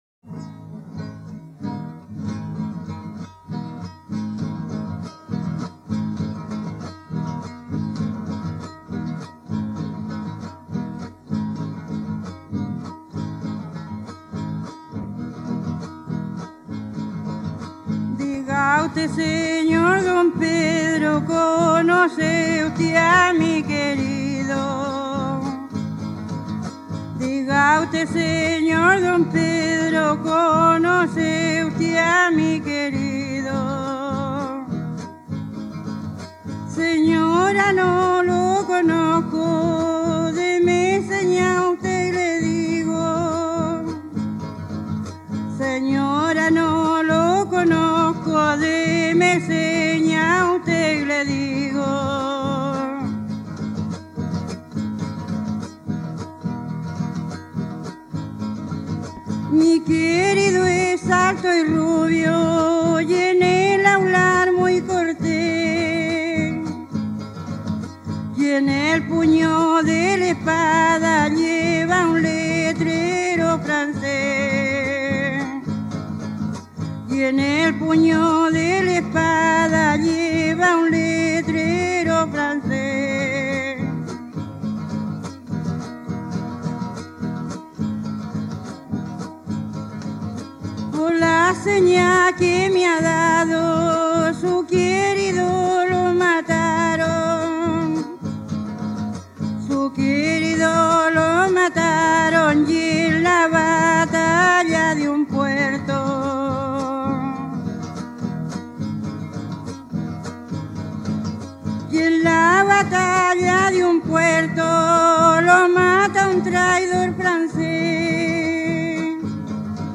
Romance en forma de tonada que trata el tema tradicional de "las señas del marido".
Música tradicional
Folklore
Tonada